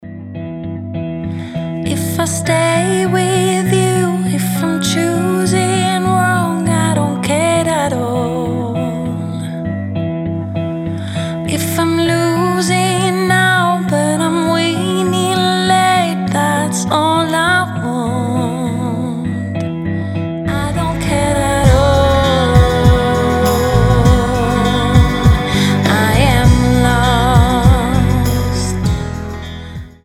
• Качество: 320, Stereo
спокойные
медленные
красивый женский голос
alternative